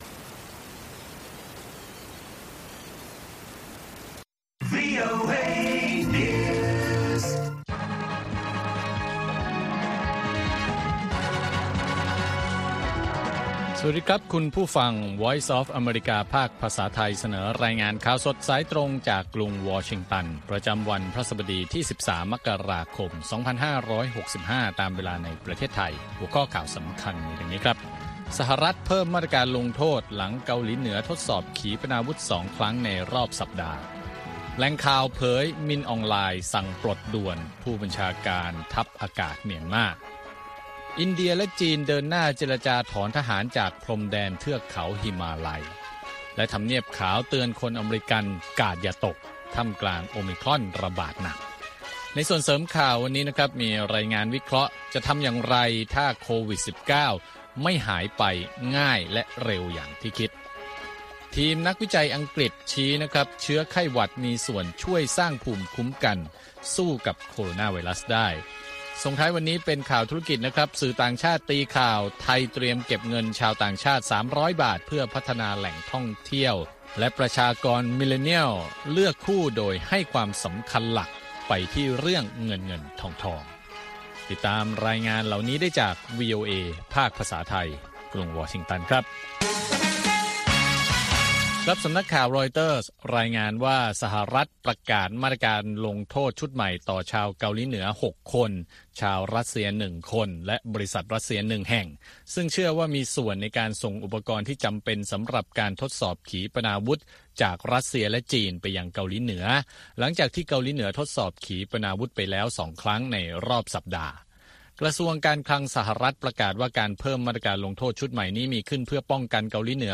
ข่าวสดสายตรงจากวีโอเอ ภาคภาษาไทย ประจำวันพฤหัสบดีที่ 13 มกราคม 2565 ตามเวลาประเทศไทย